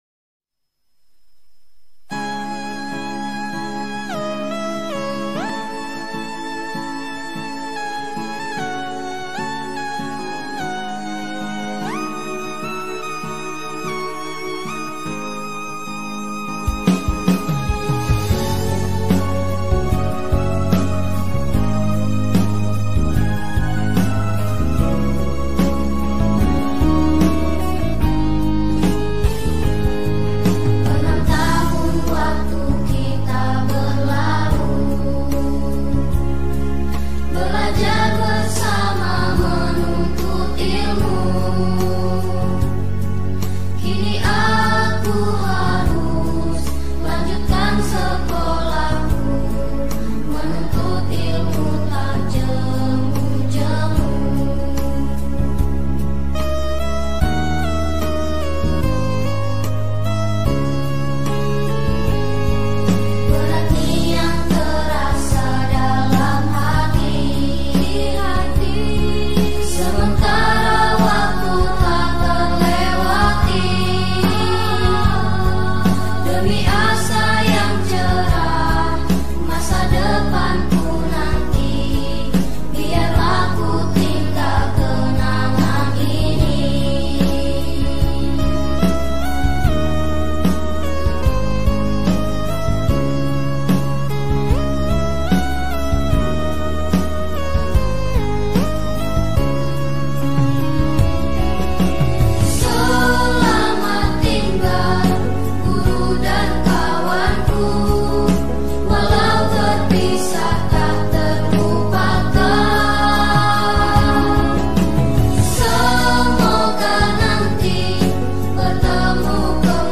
(Lagu Selamat Tinggal Guru dan Kawanku yang ada Vokal) Kelas 6 disilikan membawa tas, langsung musofahah, kemudian berbaris lagi dan secara bersamaan melambaikan tangan kepada seluruh warga sekolah.